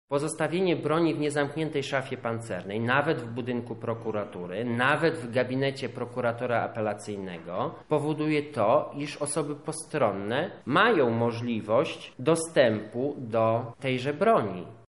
Tak wyrok uzasadnił Bernard Domaradzki, sędzia Sądu Rejonowego w Lublinie .